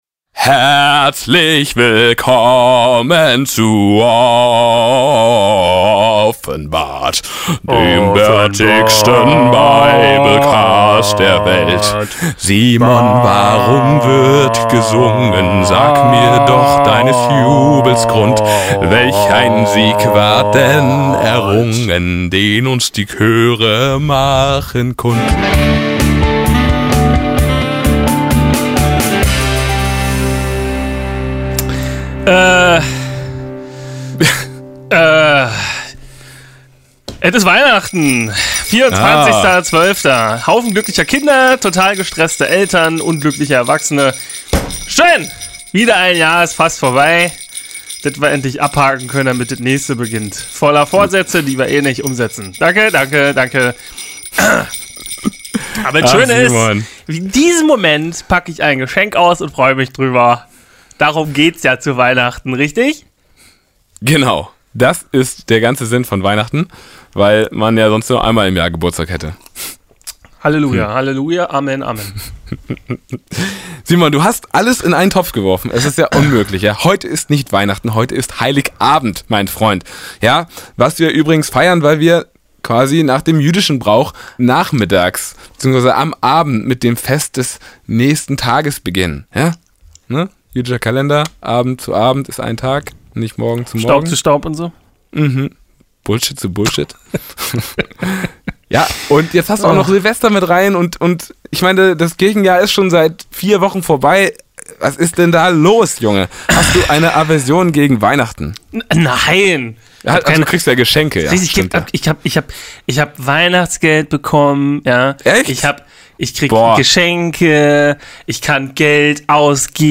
Fröhliches Caroling begrüßt euch heute zum Cast, dann versuchen wir uns (und, falls möglich, auch den Papst) der Gefahr von Jesus-Faking zu entziehen und schließlich beantworten wir die Frage, wann das Ende naht.